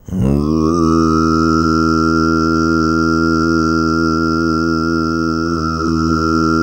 TUV3 DRONE07.wav